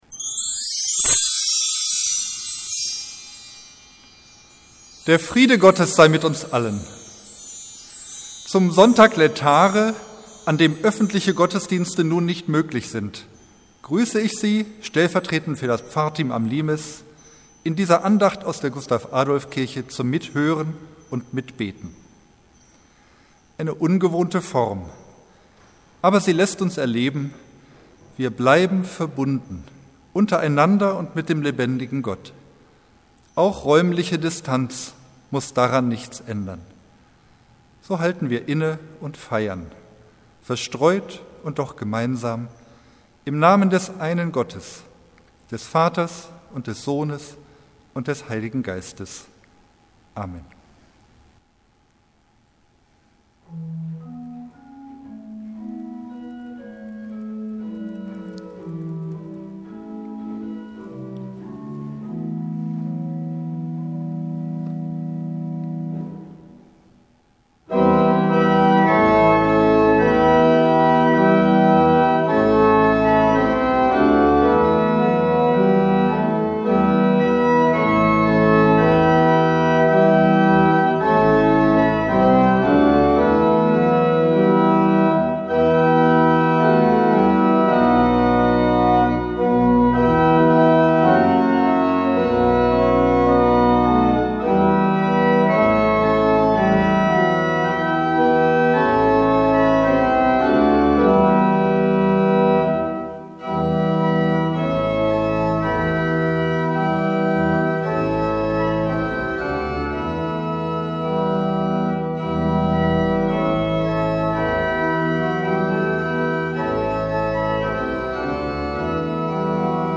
Andacht für den Sonntag Lätare, 22. März 2020